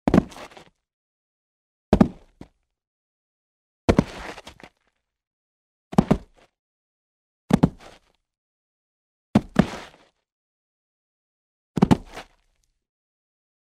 Звуки падений человека
Здесь вы найдете как комичные, так и резкие, реалистичные варианты.
1. Человек падает на землю n2. Падение человека вниз n3. Человек упал на землю n4. Падение с высоты на землю n5. Человек летит вниз